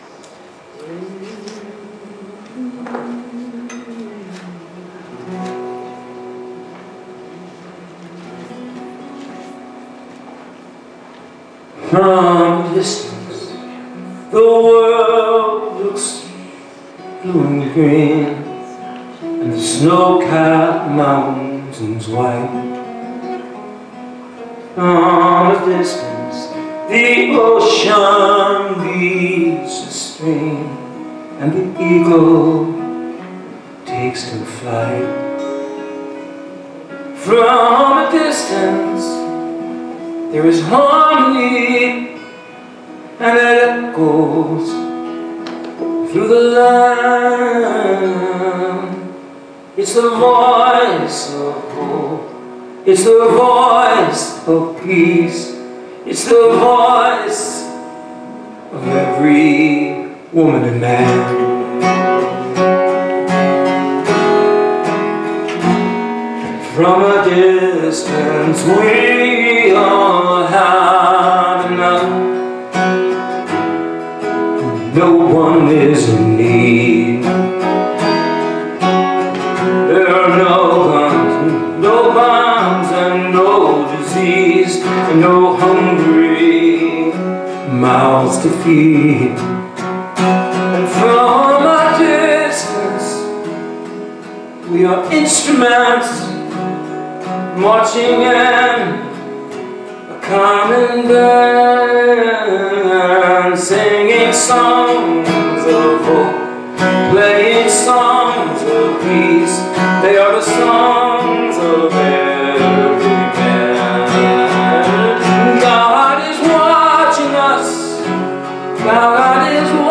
Series: Sermons 2024